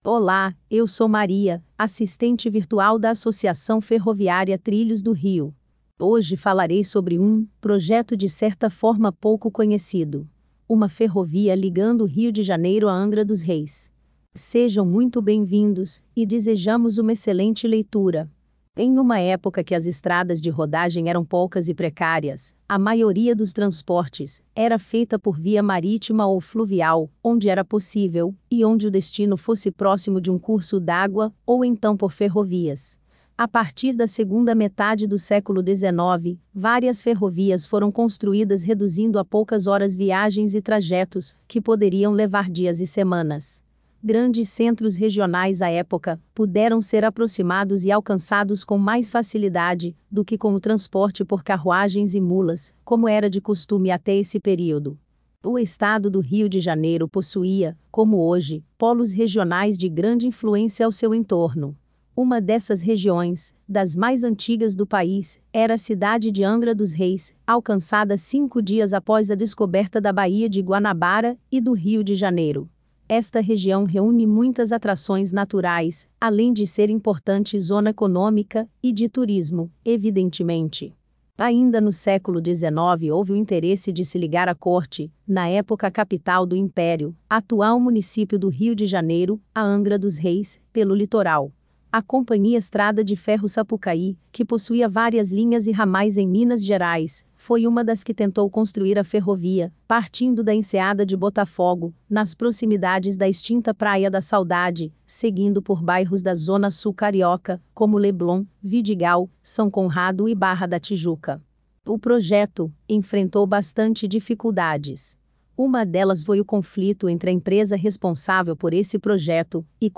[NOVIDADE] Se não puder ler, ouça este artigo clicando no player abaixo